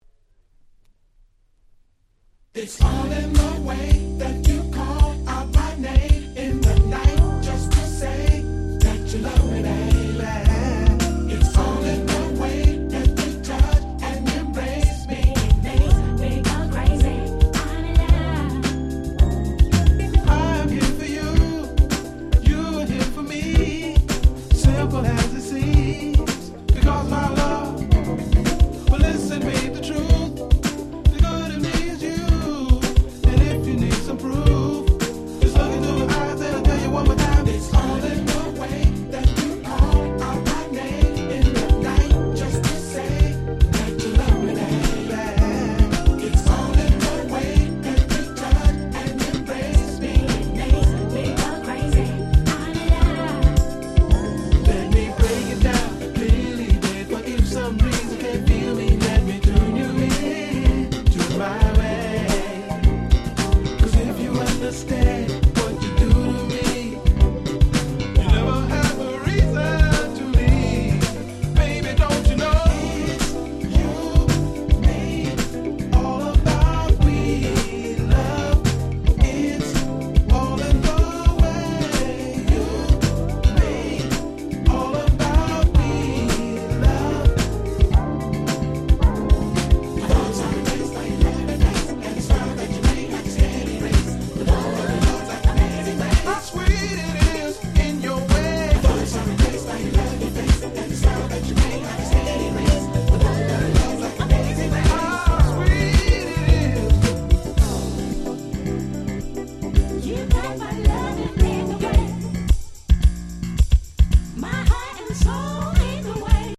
03' Nice Smooth R&B !!